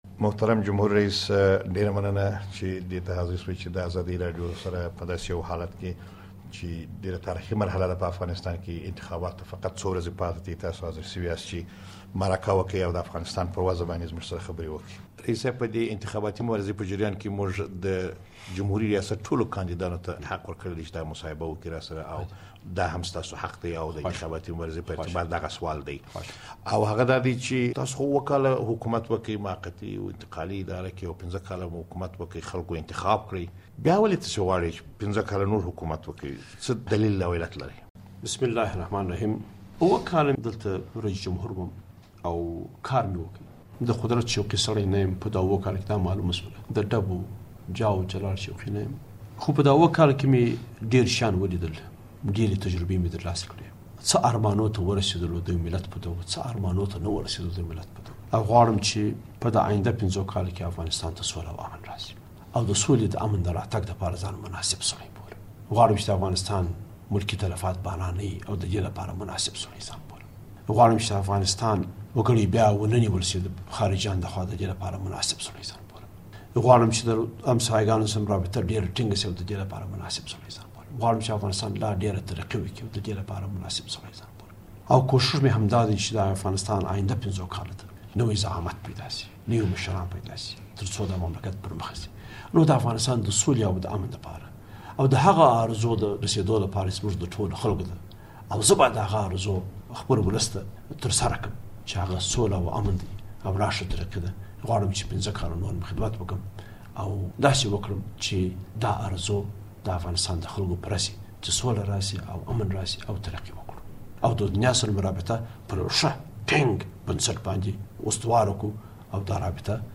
مصاحبه با حامد کرزی